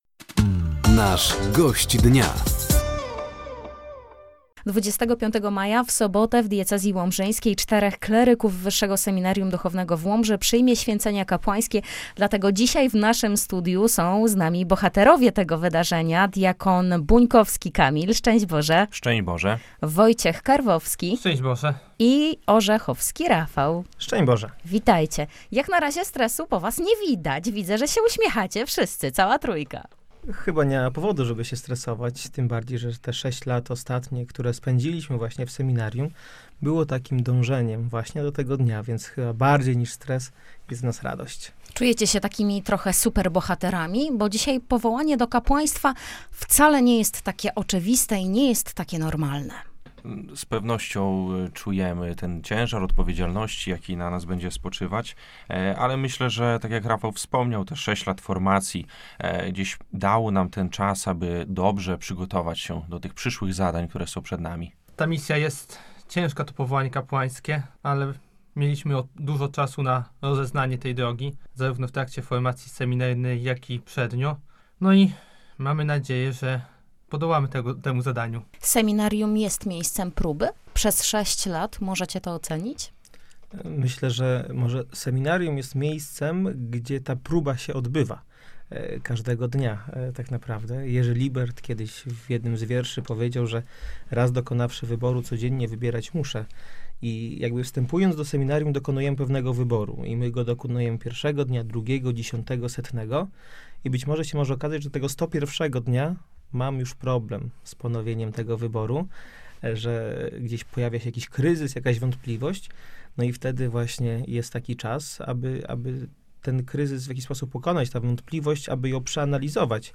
Gośćmi Dnia Radia Nadzieja byli diakoni